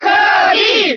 Category:Crowd cheers (SSBB) You cannot overwrite this file.
Kirby_Cheer_German_SSBB.ogg.mp3